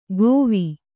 ♪ gōvi